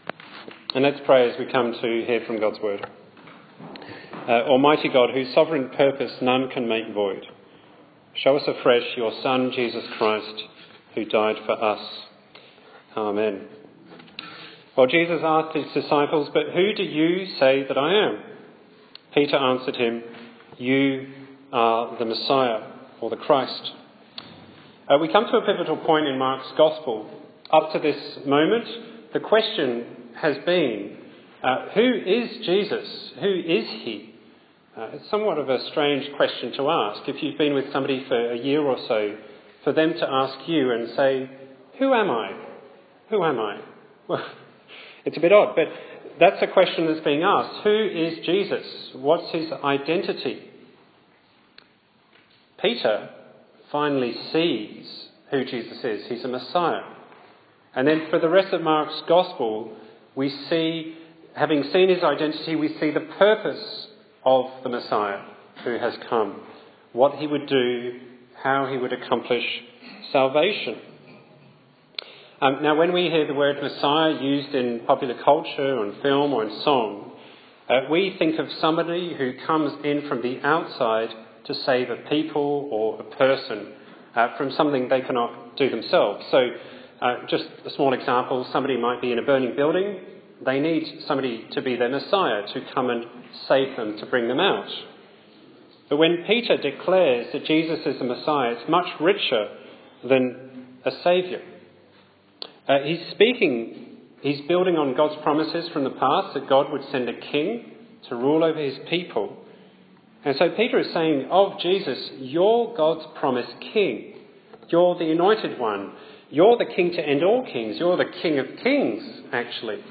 Bible Text: Mark 8:27-9:1 | Preacher